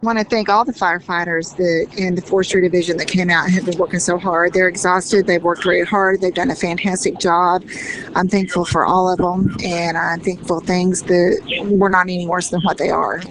Mayor Harrison says she is grateful to everyone who responded to the blaze.